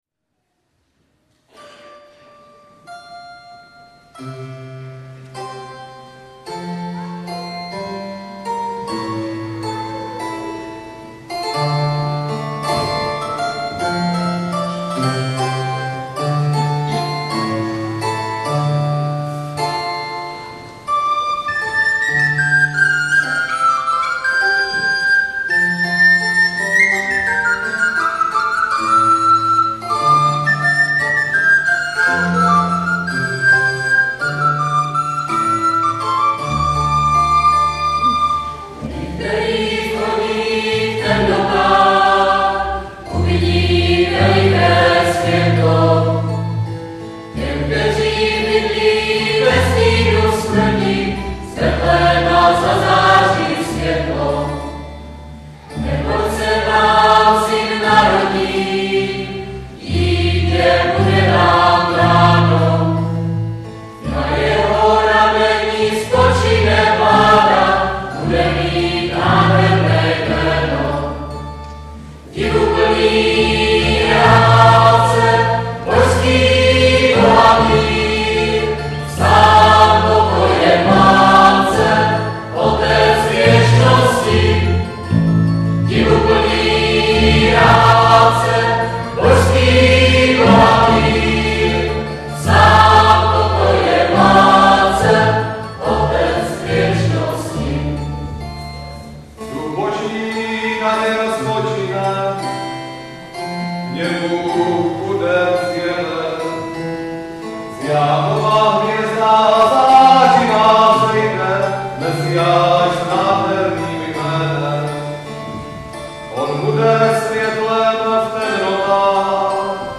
kostel Nivnice … neděla 6.1.2008